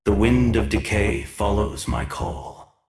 The materials I am posting here are the self-made voice effects for my custom trebuchet unit, "Kukulkan Catapult"!
These voices were recorded by myself and produced using Sovits' voice tone replacement.